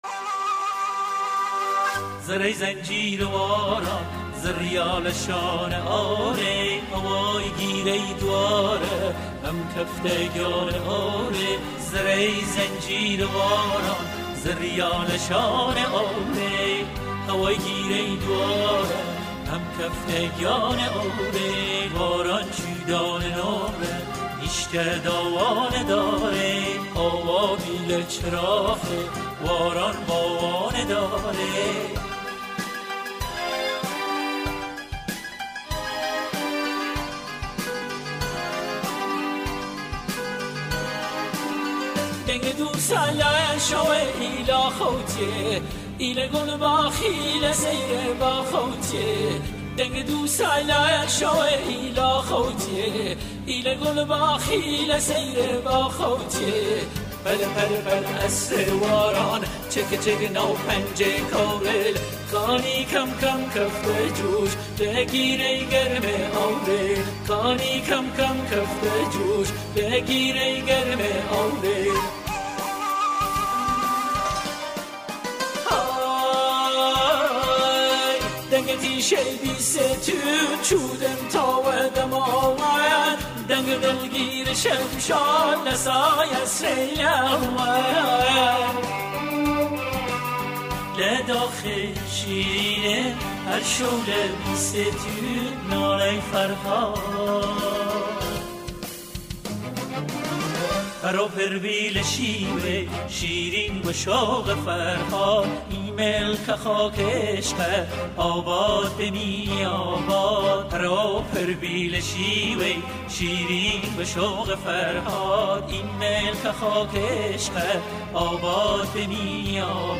گۆرانی کوردی ئیلامی